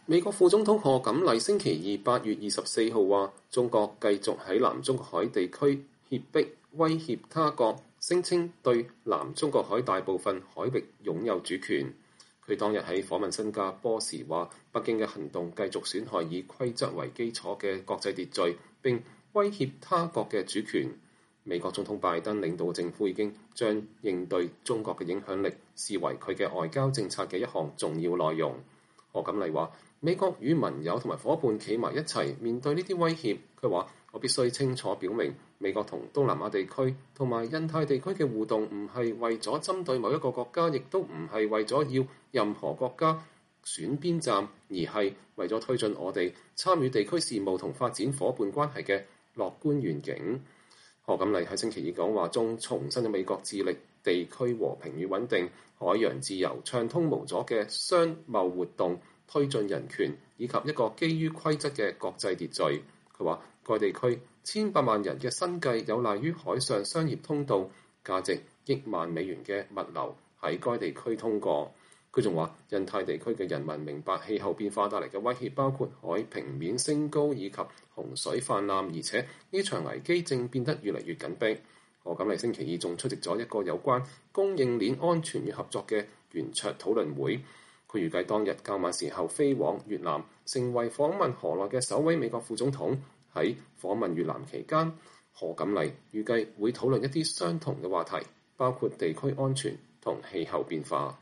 她當天在訪問新加坡時說，北京的行動“繼續損害以規則為基礎的國際秩序，並威脅他國的主權。“